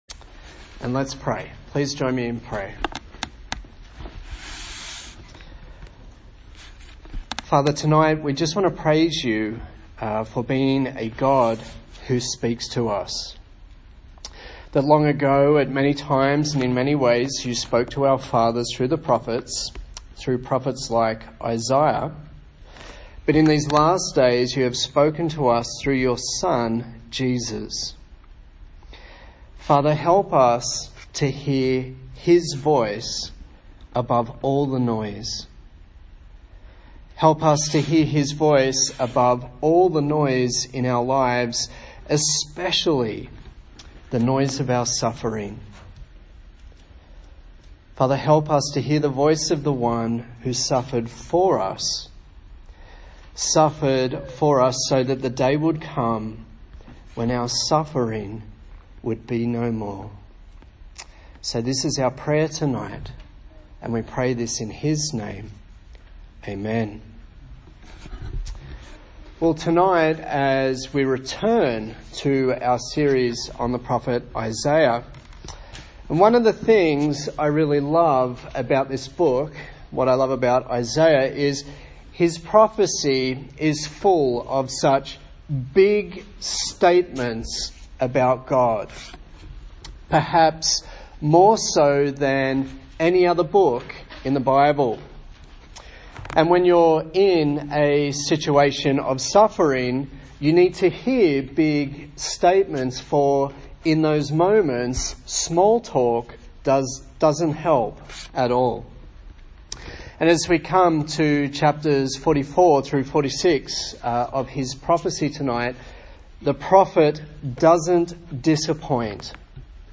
Service Type: TPC@5 A sermon in the series on the book of Isaiah